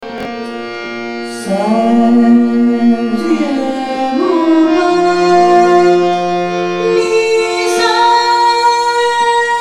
ThaatKafi
ArohaS R m P N S’